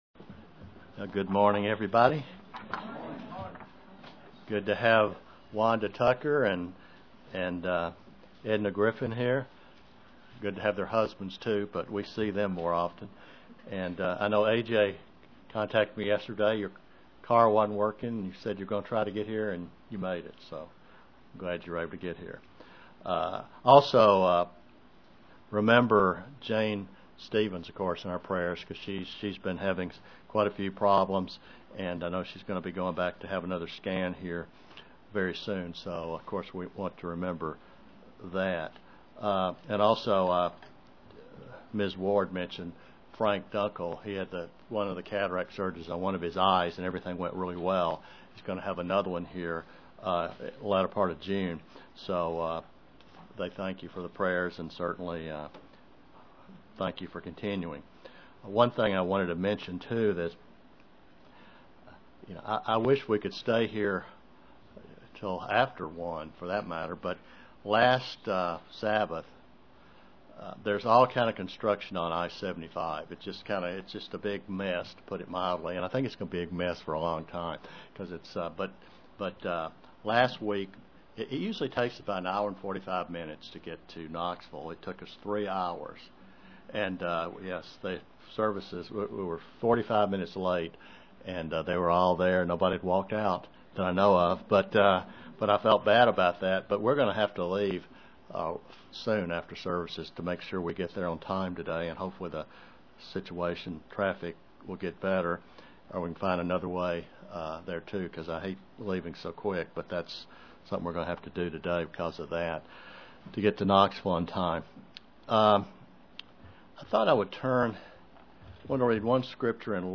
The Bible describes the Holy Spirit as the power of God and not a third person in the Godhead. God is a family, consisting currently of the Father and Son; not a trinity. (Presented to the London KY, Church)